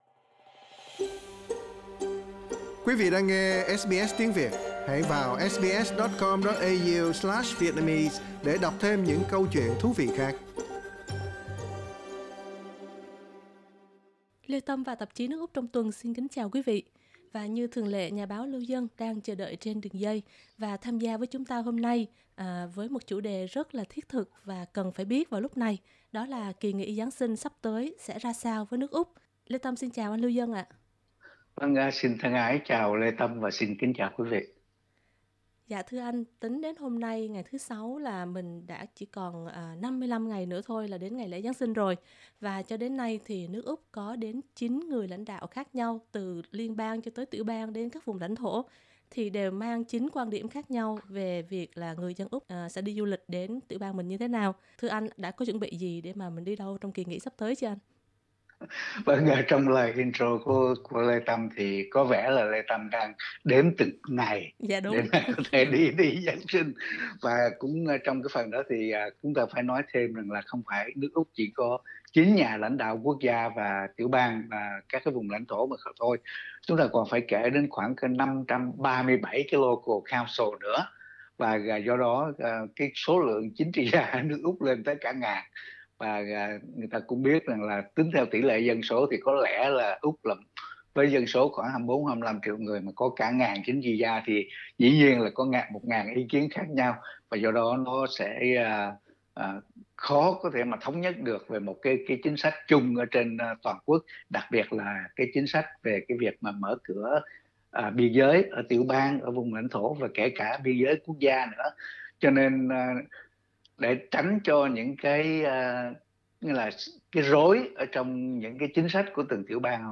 Xin mời quý vị bấm vào biểu tượng audio để nghe toàn bộ bài phỏng vấn.